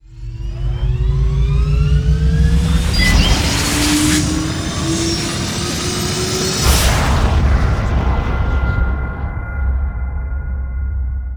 shipassend.wav